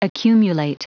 Prononciation du mot accumulate en anglais (fichier audio)
Prononciation du mot : accumulate